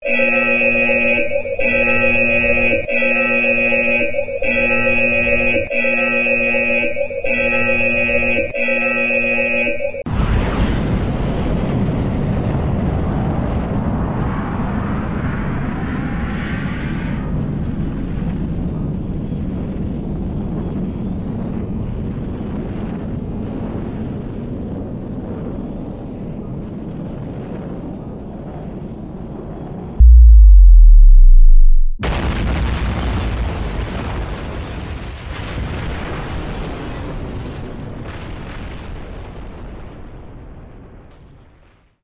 描述：炸弹从报警到发射，再到撞击，再到善后。
Tag: 炸弹 爆炸